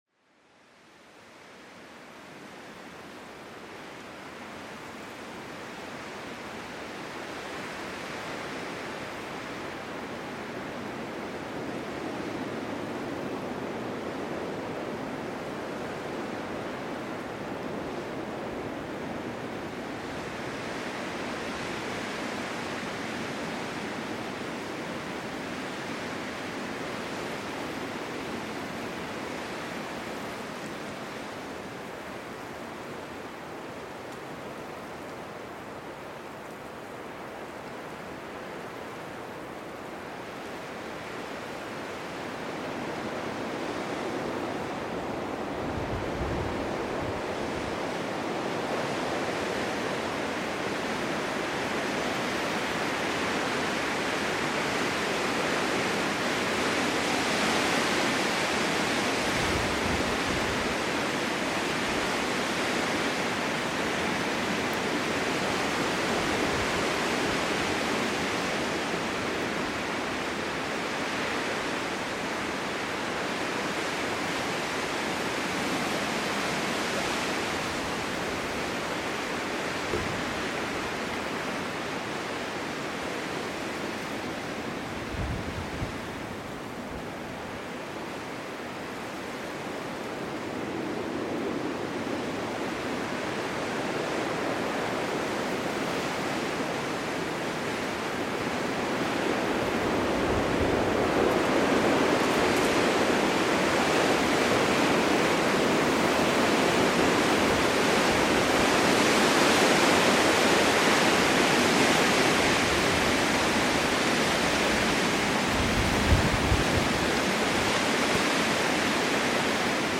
60-SEKUNDEN-PHÄNOMEN: Winterorkan schafft unaufhaltsame Ruhe